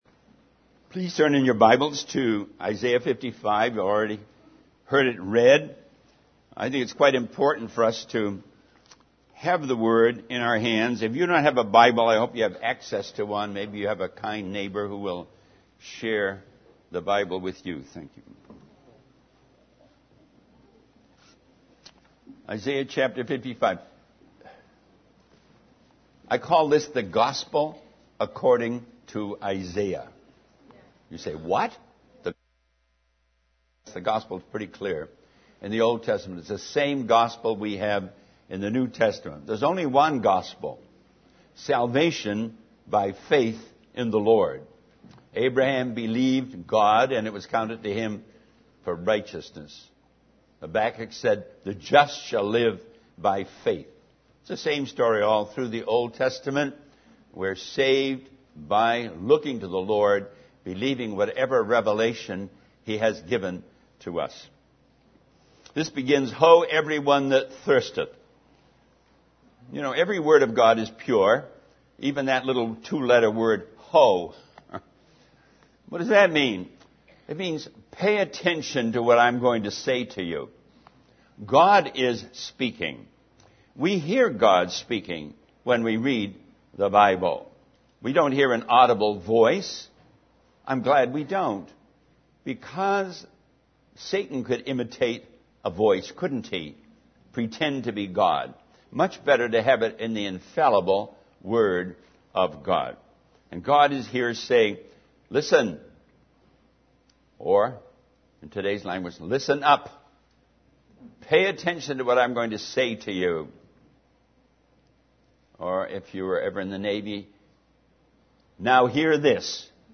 In this sermon, the preacher shares a story about a couple who lived empty lives, focused on material pleasures and entertainment. He emphasizes the tragedy of people living their lives like hamsters on a wheel, going around in circles and getting nowhere. The preacher then discusses the transformation that occurs when Jesus comes into a person's heart, comparing it to a briar bush becoming a cypress tree.